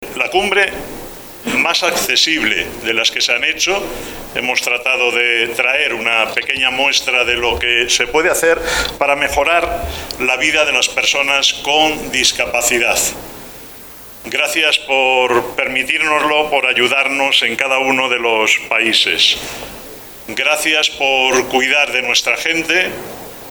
ante el rey Felipe VI y  los responsables políticos de la Cumbre.